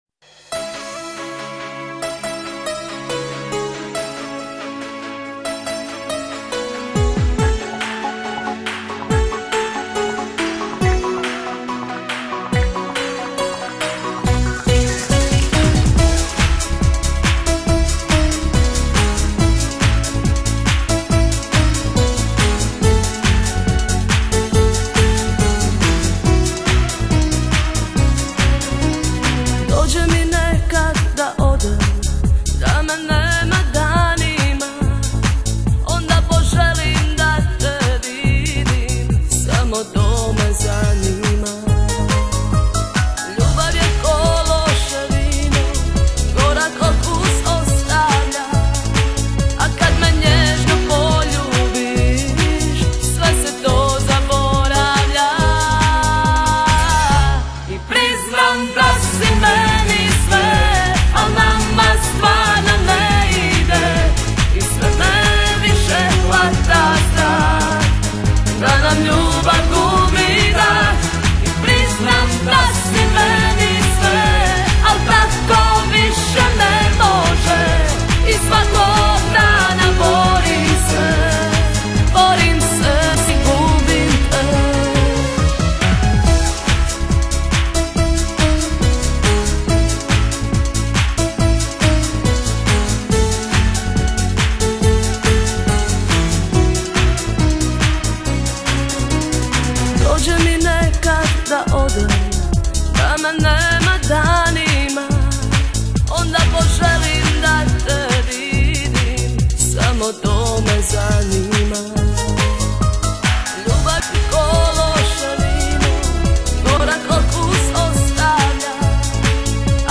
lo-fi, stereo